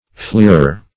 \fleer"er\ (fl[=e]r"[~e]r)